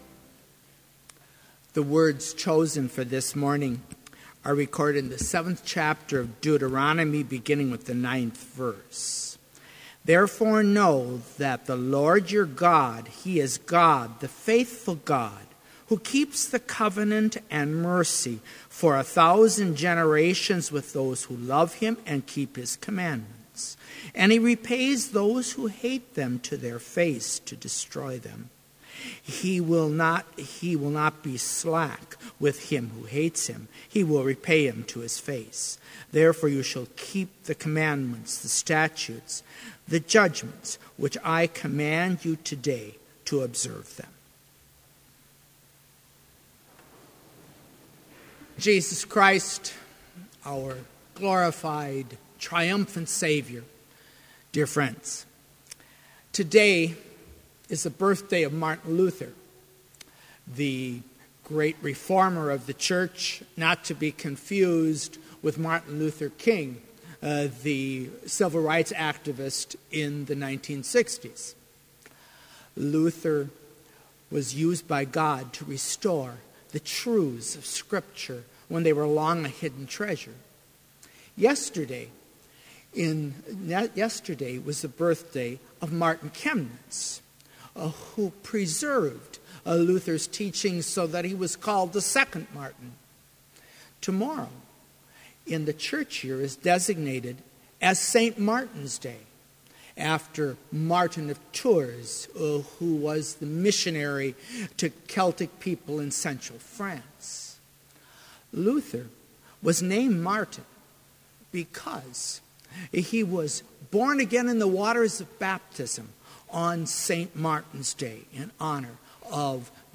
Complete service audio for Chapel - November 10, 2015